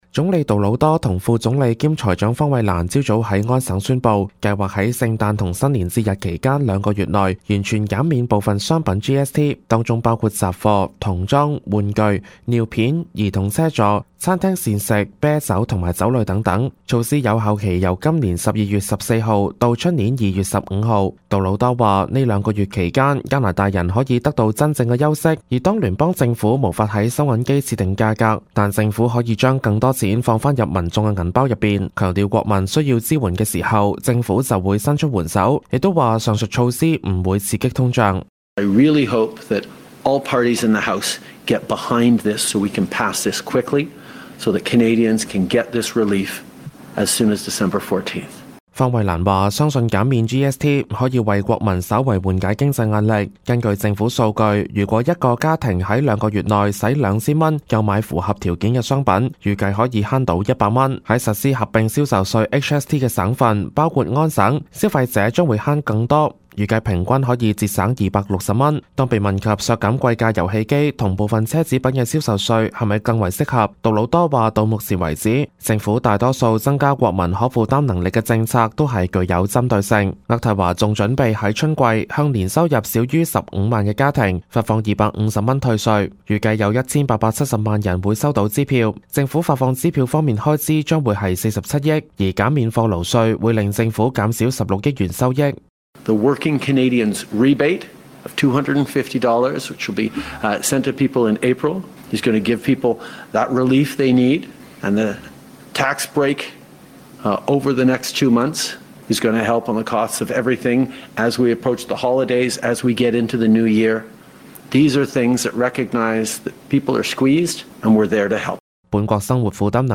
Canada/World News 全國/世界新聞
粵語